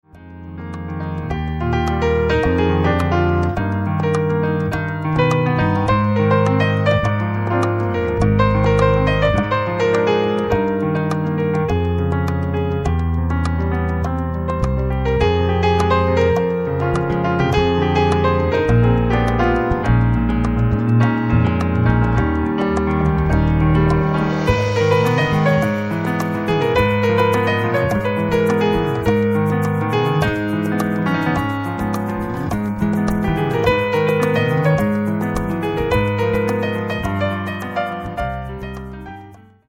PIANO TRIO
甘く儚く、切なげに舞い上がるミラクルなメロディの応酬。